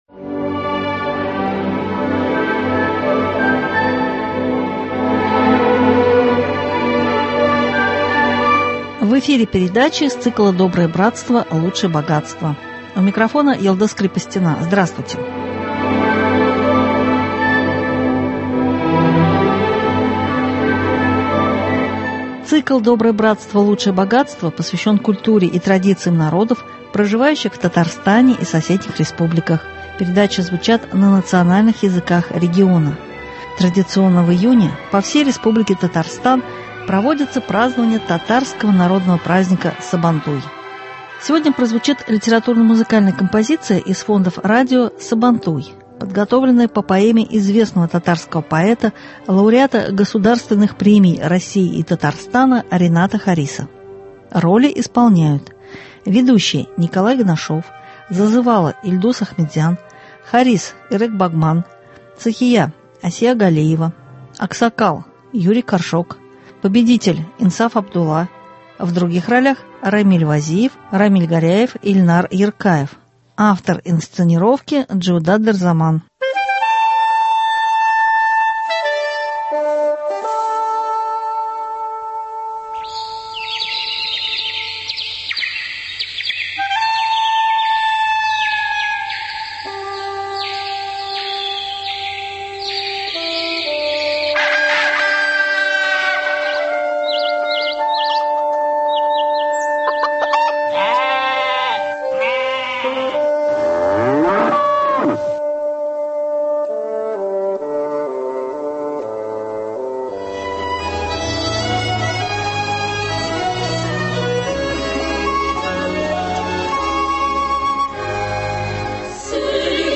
Сегодня прозвучит радиокомпозиция из фондов радио Сабантуй , подготовленная по поэме известного татарского поэта, лауреата Госпремий России и Татарстана Рената Хариса.